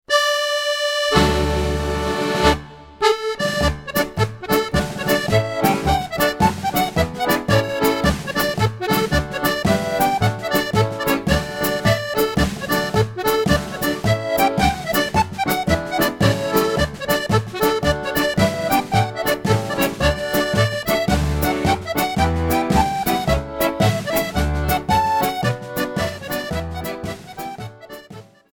accordion
drums